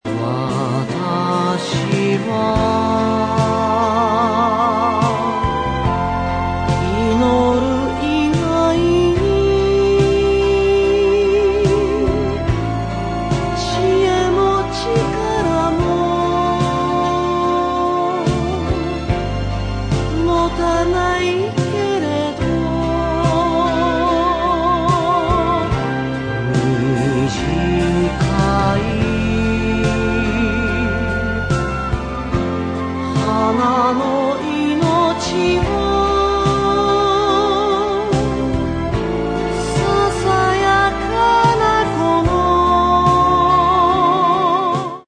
心持ちライトタッチな曲が集められたアルバム。